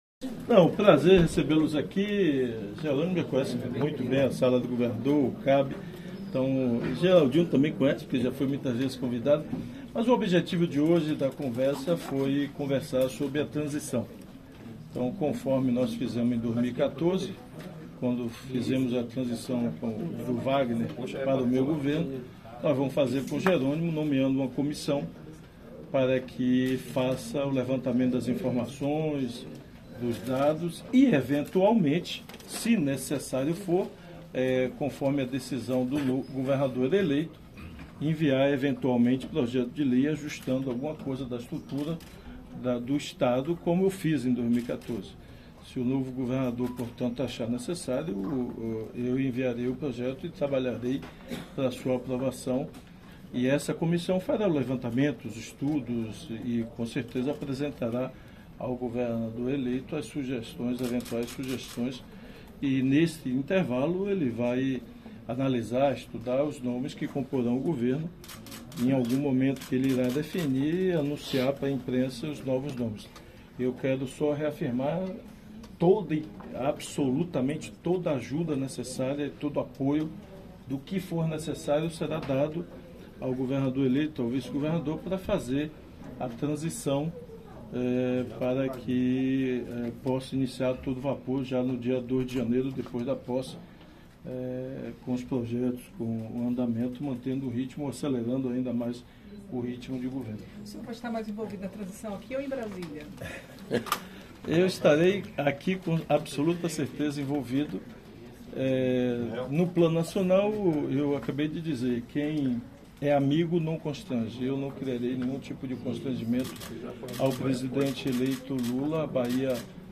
Após a reunião, a imprensa foi recebida em uma entrevista coletiva.